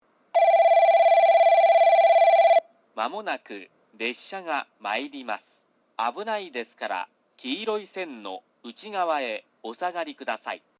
2番のりば接近放送　男声